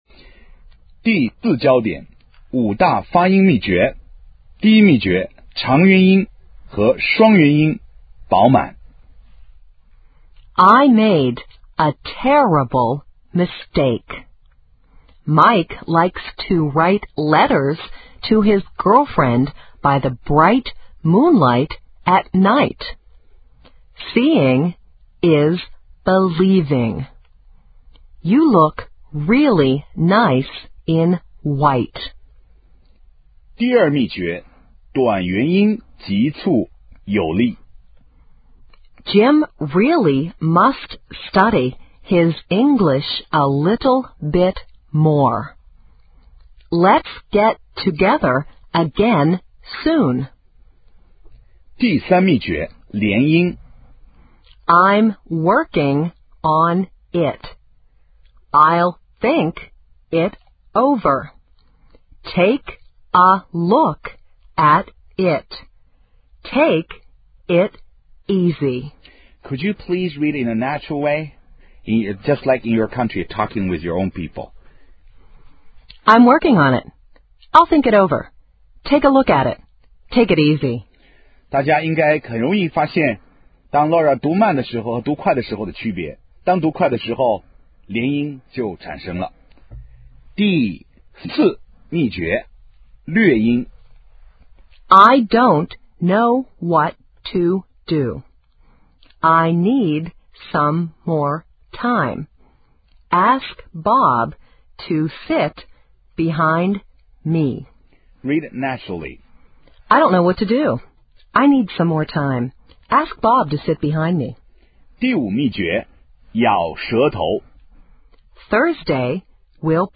五大发音秘诀
它们是：长元音和双元音饱满；短元音急促有力；连音；略音和咬舌头。
【七次疯狂张嘴，元音极其饱满】
【超级长元音，特别过瘾！】